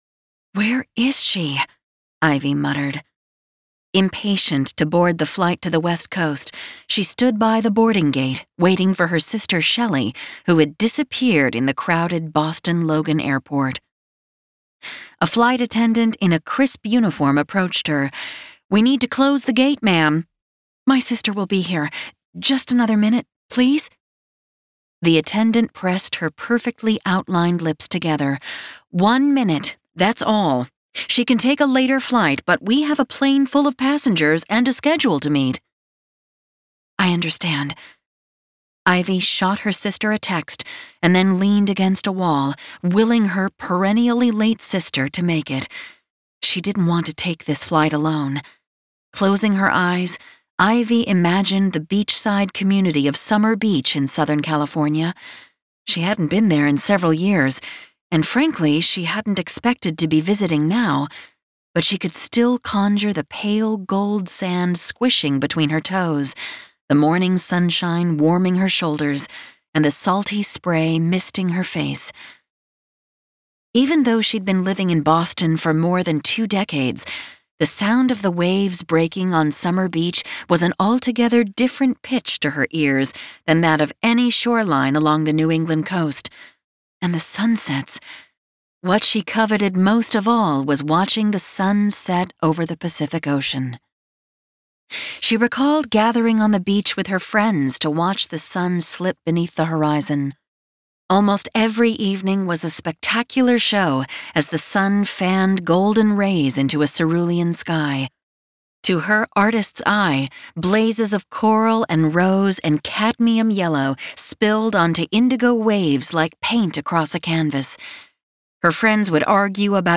• Audiobook
00_Seabreeze Inn_Retail Sample.mp3